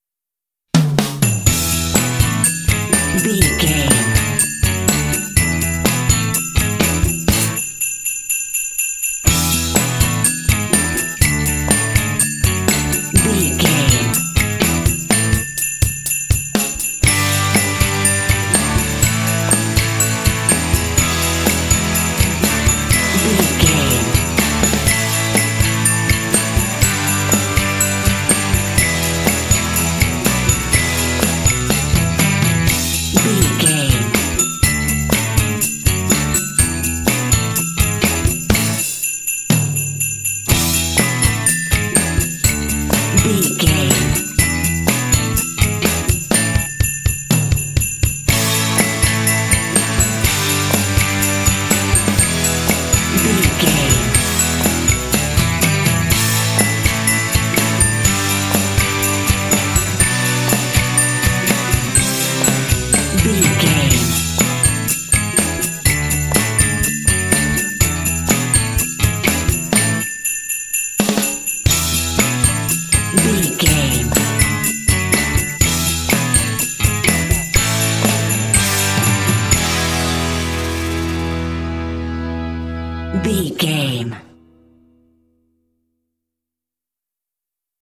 Ionian/Major
happy
festive
joyful
drums
bass guitar
electric guitar
contemporary underscore